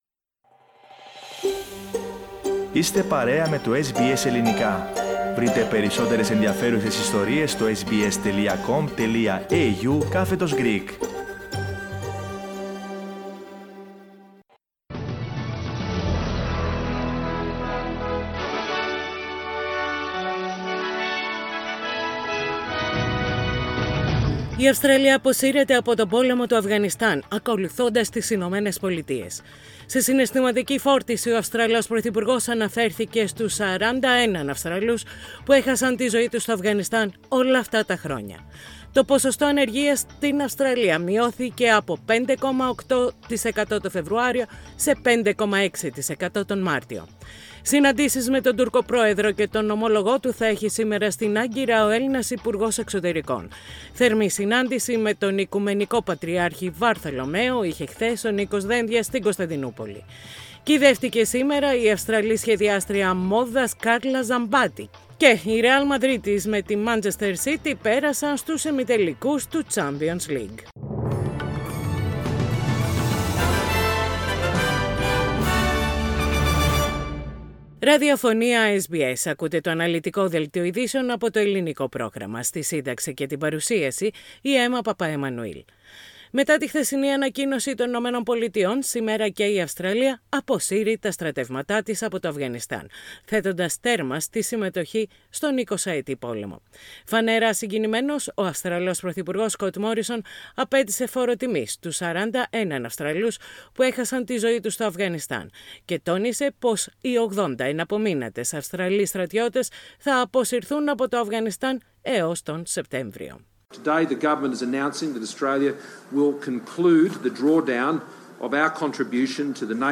Ειδήσεις στα Ελληνικά - Πέμπτη 15.4.21
Οι κυριότερες ειδήσεις της ημέρας από το Ελληνικό πρόγραμμα της ραδιοφωνίας SBS.